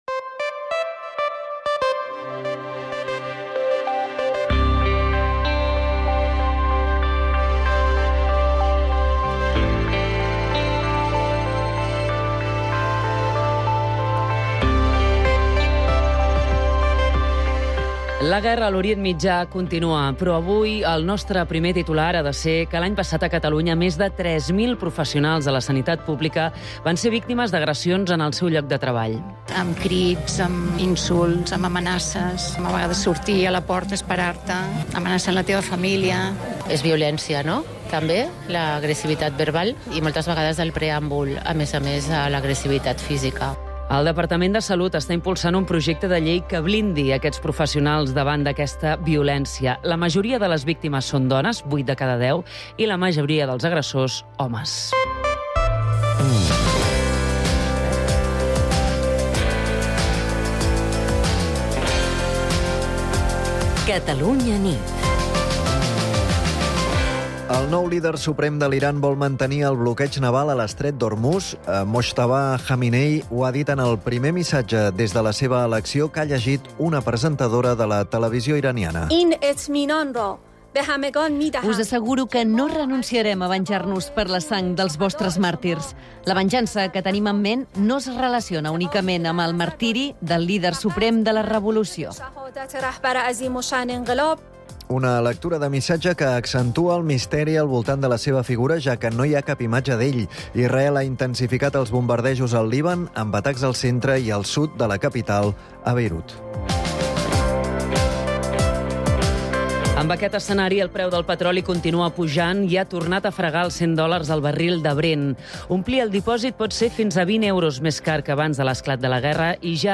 També sentim el testimoni de treballadors de la sanitat pública catalana. L'any passat aquests professionals van denunciar més de 3.000 agressions a la feina.